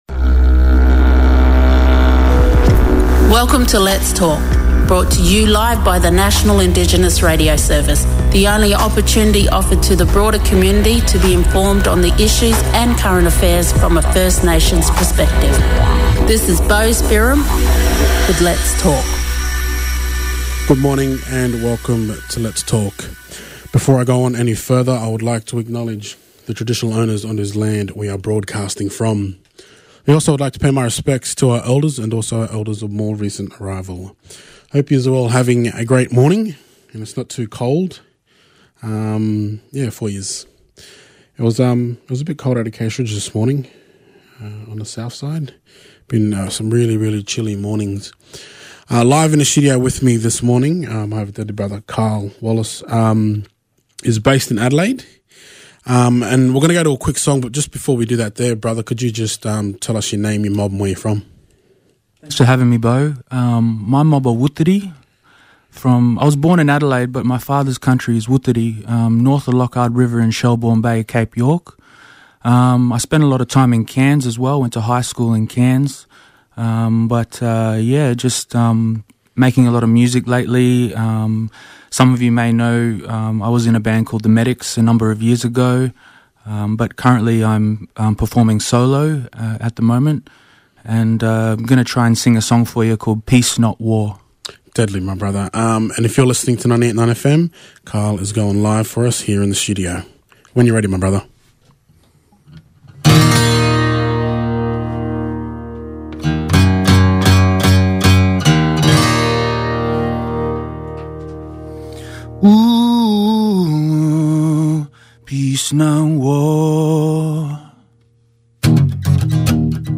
On today show we also yarn about his solo journey as an artist he sung two deadly song for us.